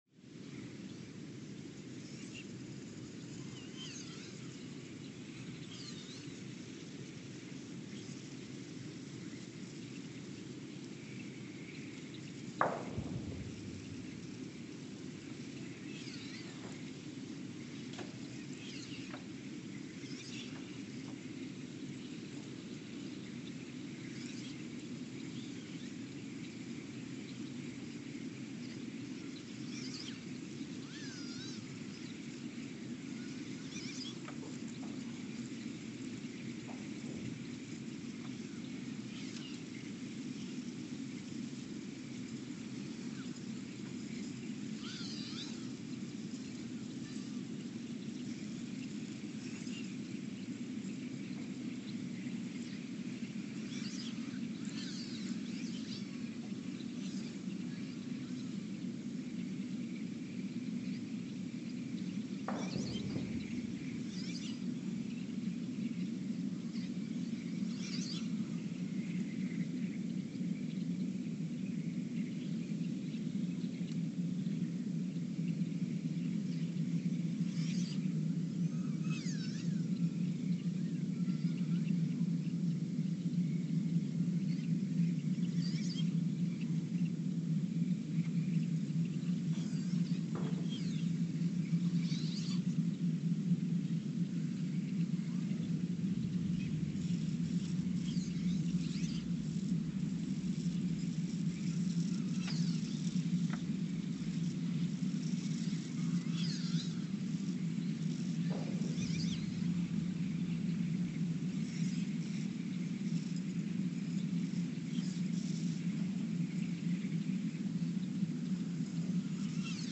The Earthsound Project: Ulaanbaatar, Mongolia (seismic) archived on August 12, 2020
The Earthsound Project is an ongoing audio and conceptual experiment to bring the deep seismic and atmospheric sounds of the planet into conscious awareness.
Speedup : ×900 (transposed up about 10 octaves) Loop duration (real) : 168 hours